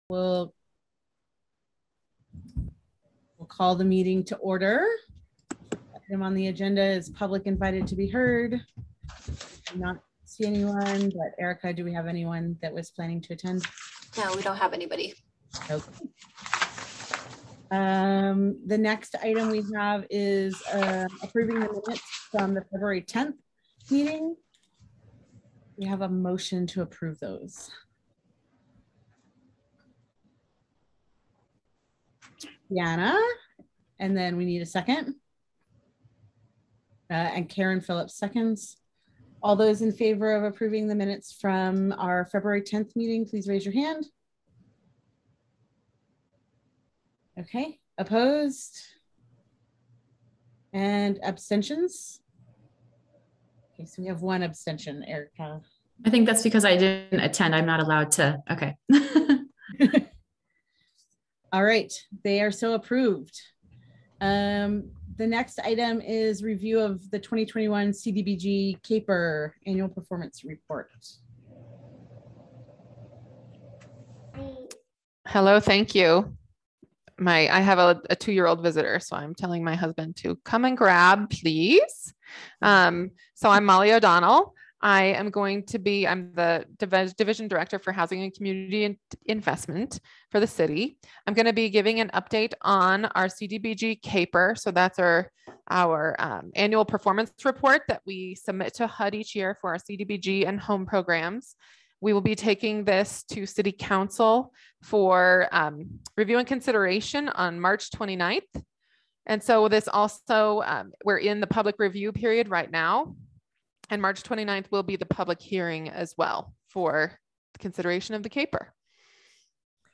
The Housing and Human Services Advisory Board Meeting recorded on March 10, 2022